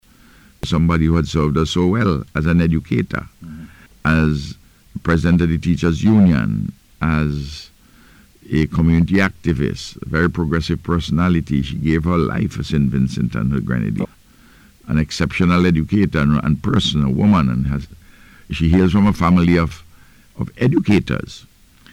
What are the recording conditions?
He made this statement during the Face to Face programme aired on NBC Radio this morning.